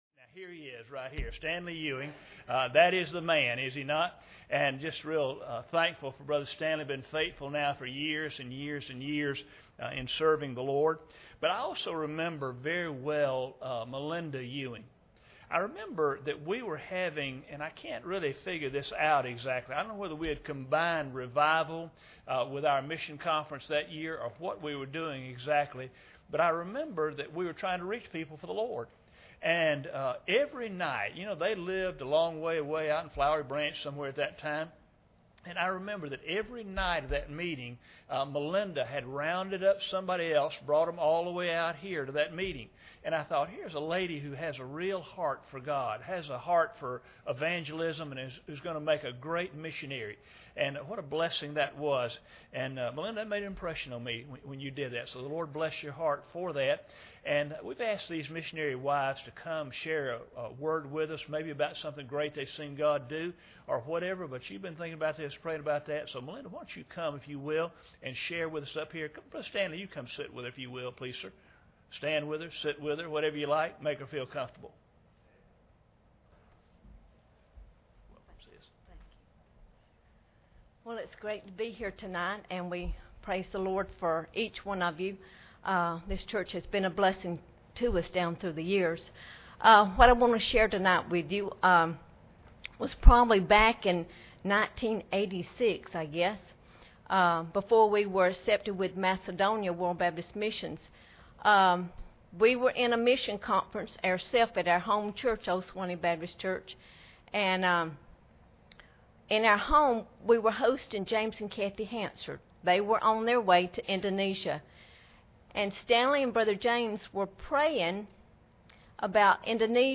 2015 Missions Conference Service Type: Wednesday Evening Preacher